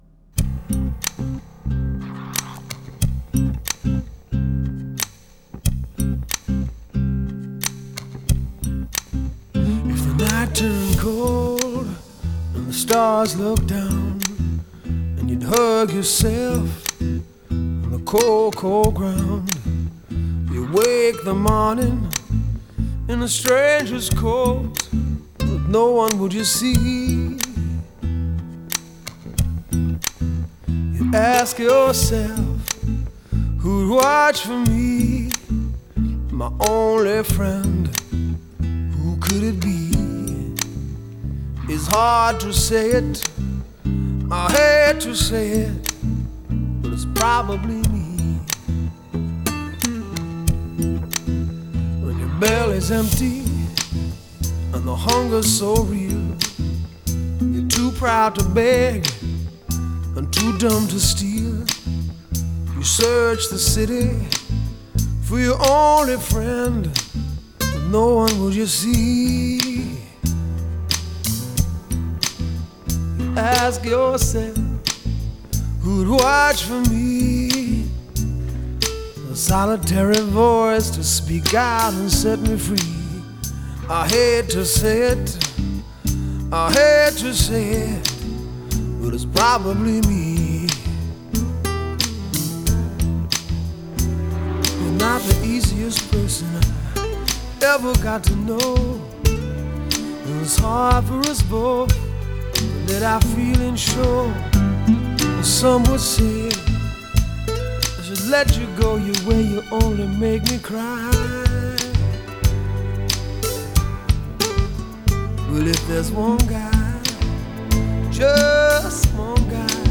Genre: New Wave
sultry groove